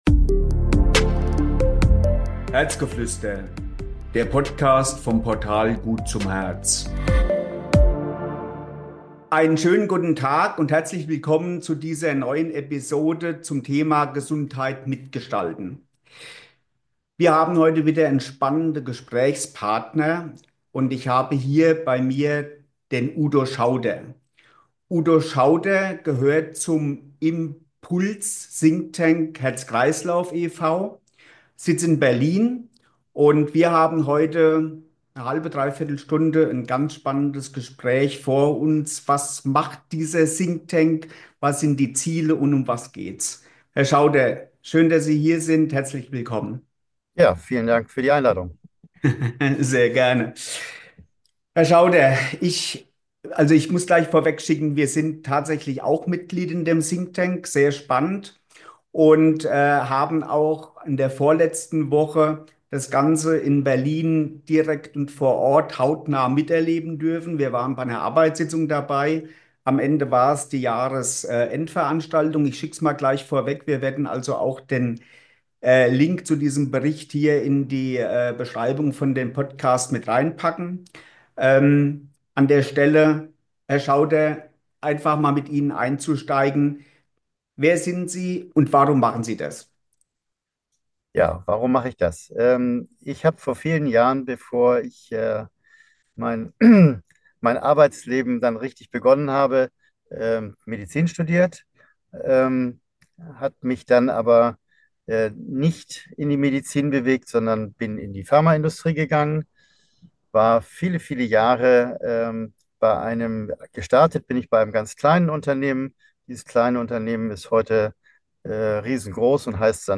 Ein spannendes und motivierendes Gespräch über die Bedeutung von Vernetzung und gemeinsamen Anstrengungen, um Herz-Kreislauf-Erkrankungen stärker in den Fokus der Gesellschaft und Politik zu rücken.